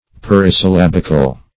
Search Result for " parisyllabical" : The Collaborative International Dictionary of English v.0.48: Parisyllabic \Par`i*syl*lab"ic\, Parisyllabical \Par`i*syl*lab"ic*al\, a. [Pari- + syllabic, -ical: cf. F. parisyllabique.]